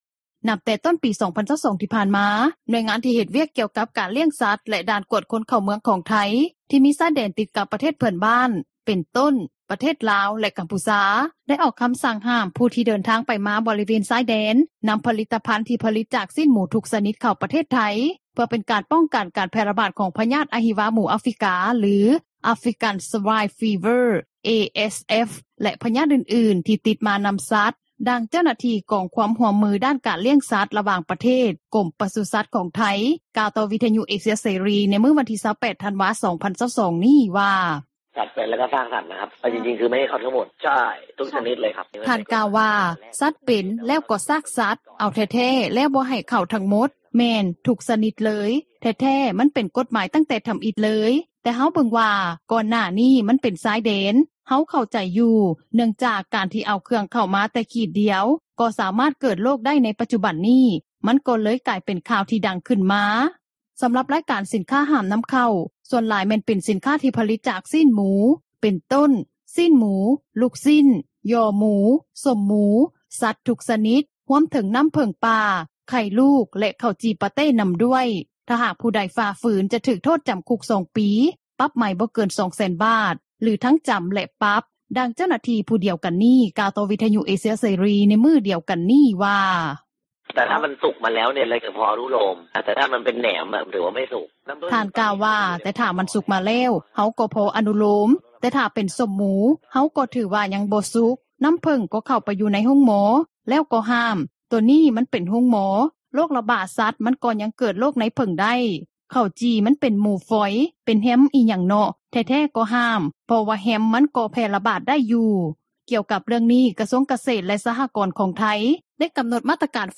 ດັ່ງຊາວນະຄອນຫຼວງວຽງຈັນ ນາງນຶ່ງ ກ່າວວ່າ: